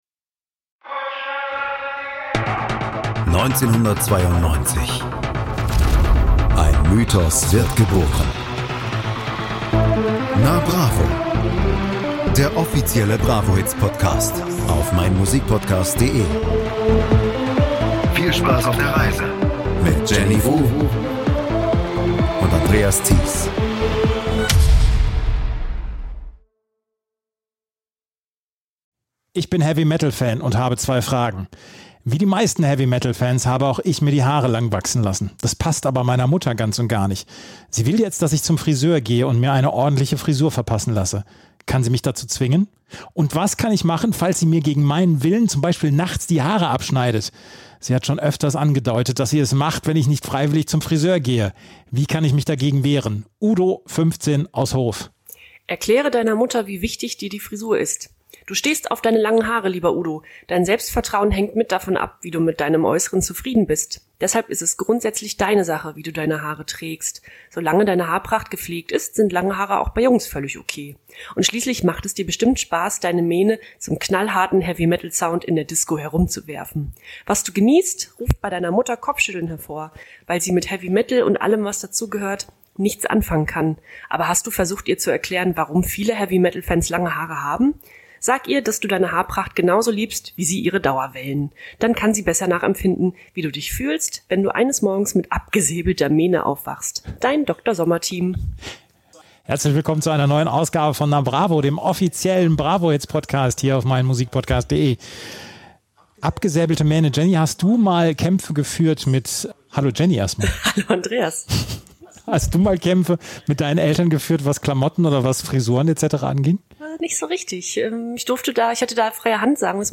Die Titel werden abwechselnd vorgestellt und in die Vorauswahl der beiden auch wieder reingehört.